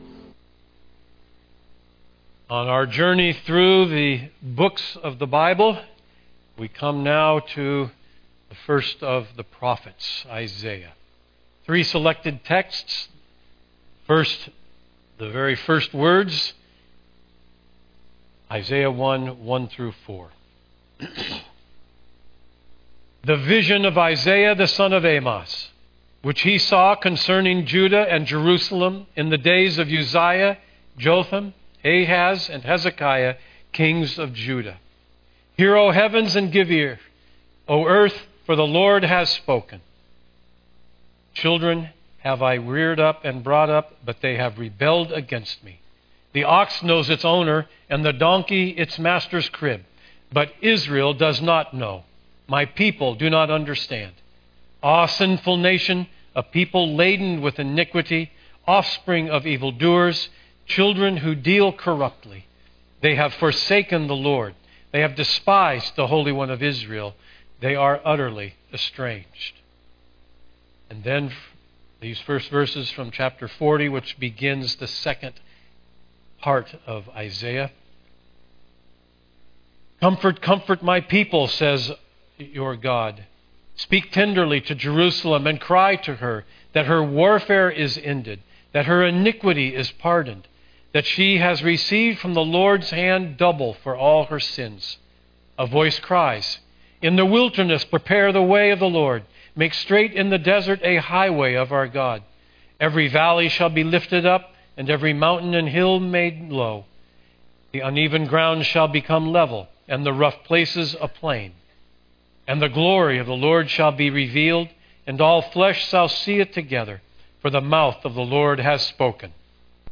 Text for Sermon: Isaiah 1:1-4; 40:1-5, 9; 53:3-6